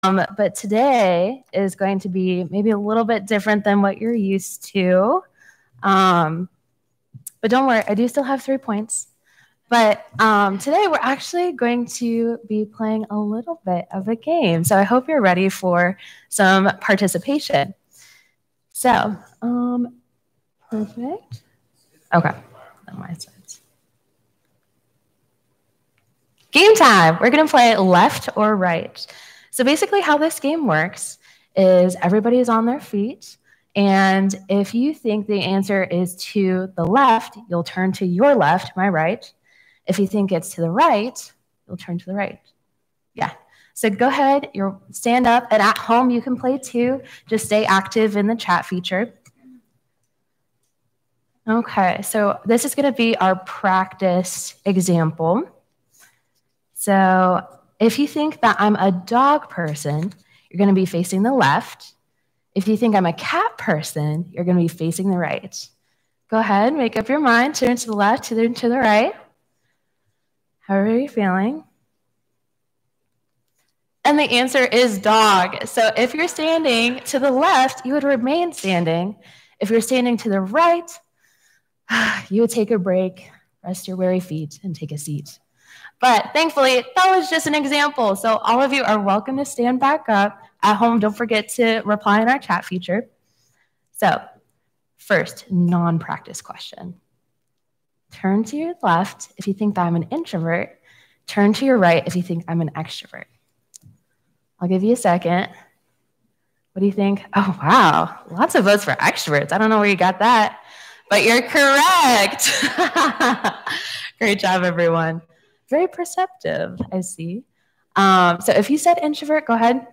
Sermon-7.12.20.mp3